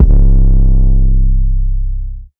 MZ 808 [Carousel].wav